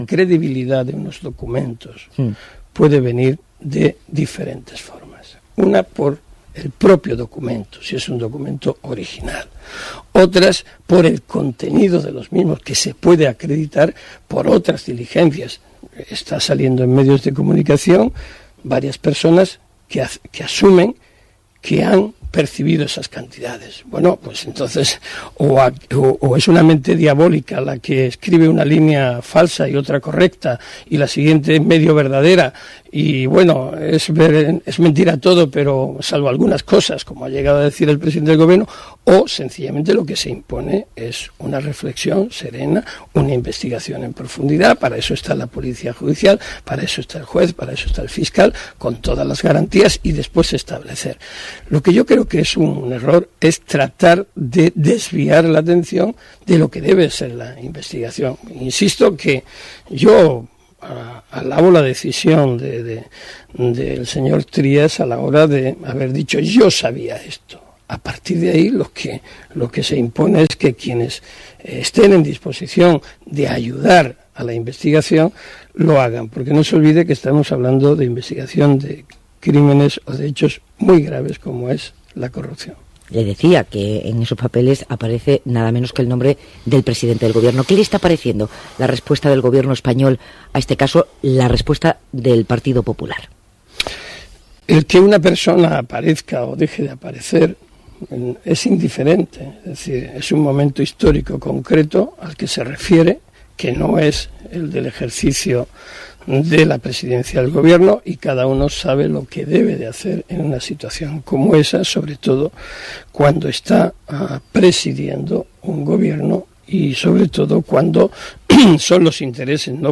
Entrevista a Baltasar Garzón en la Cadena SER el 8/2/2013